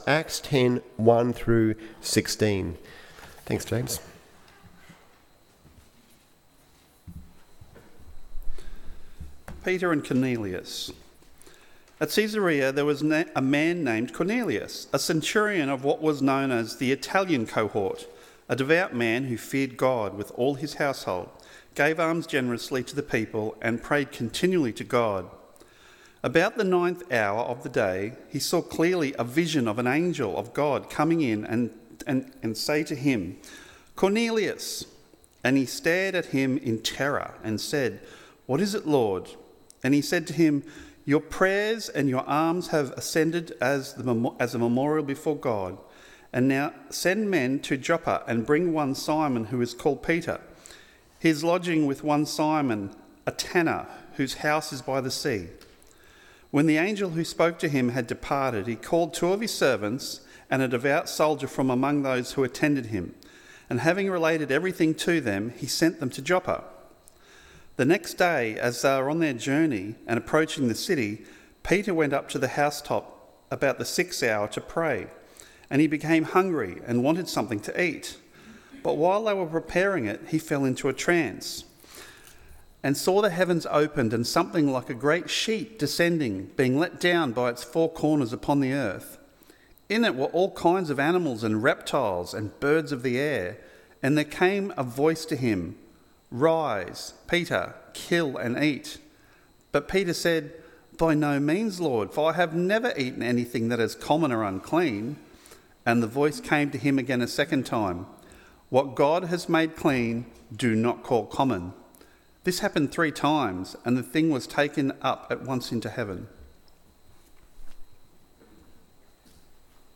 Good News For Everyone PM Service